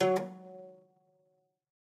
kora.ogg